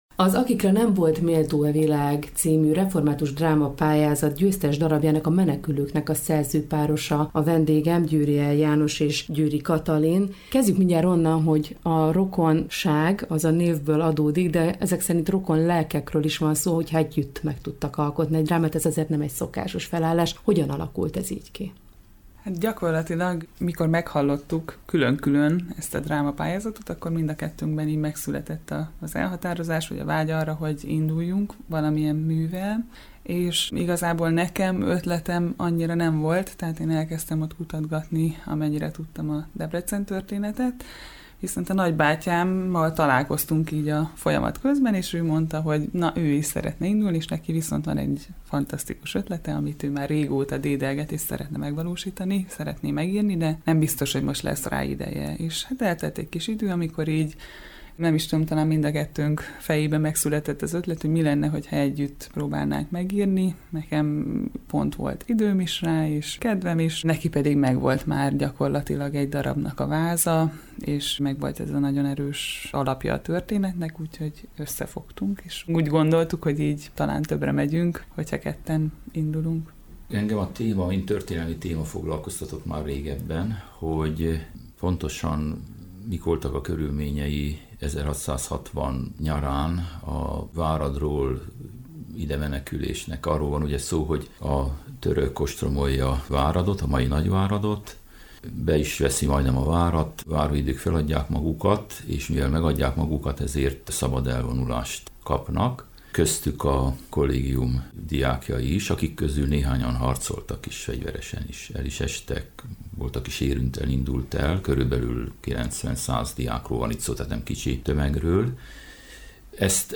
ref-dramapaly-szerzok.mp3